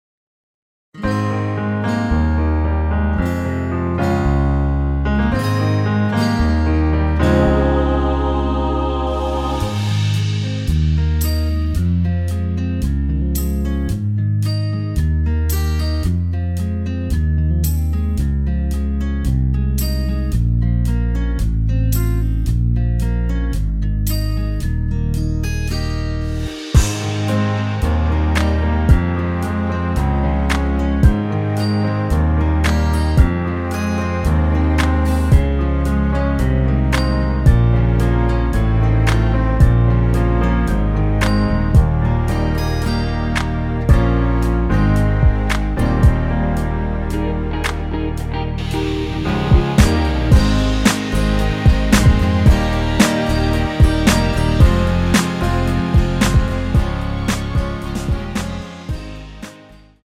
(-2) 내린 MR 입니다.(미리듣기 참조)
Ab
앞부분30초, 뒷부분30초씩 편집해서 올려 드리고 있습니다.
중간에 음이 끈어지고 다시 나오는 이유는